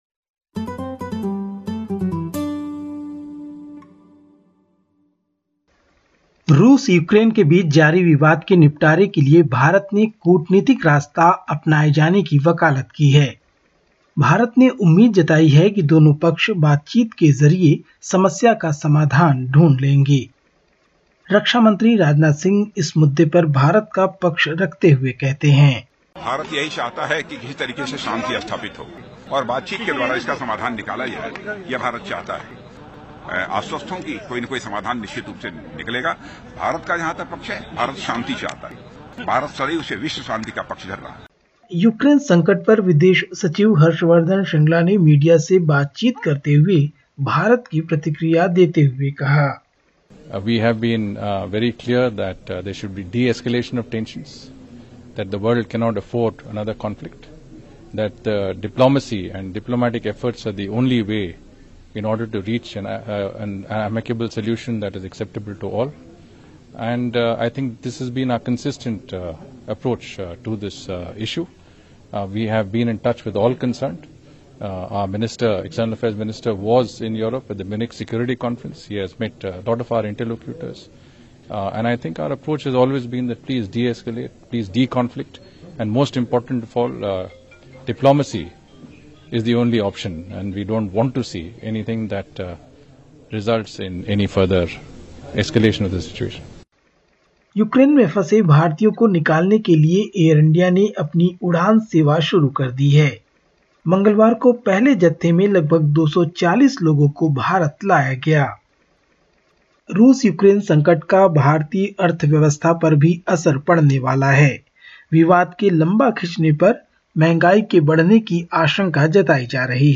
Listen to the latest SBS Hindi report from India. 23/02/2022.